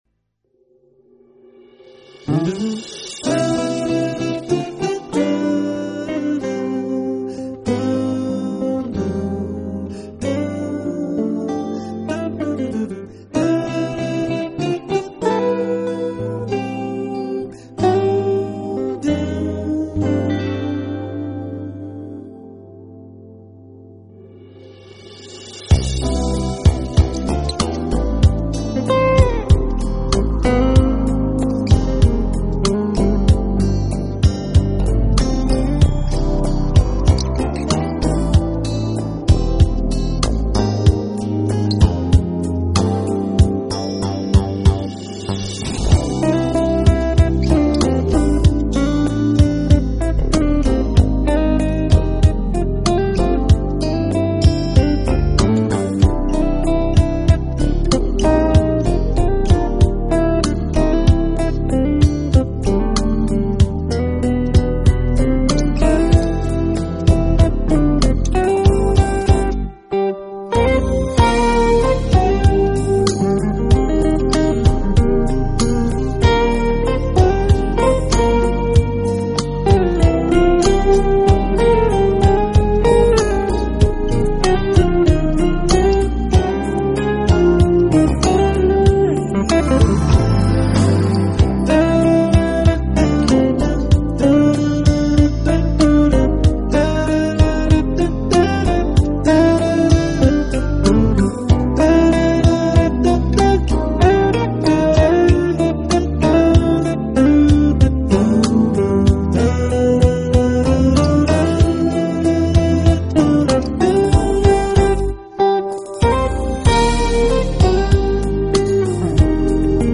起，时而低沉，一切就是那么慵懒的平滑的随着吉他琴弦的波动，而慢慢起伏自己的状
全碟作品是以吉他为主乐器，配合爵士乐固有的乐器，如钢琴，敲击乐器，萨克斯，黑管，
作品中，偶然会有人声的歌唱，更是给这种特有的营造的氛围添加一个高潮，浪漫的，如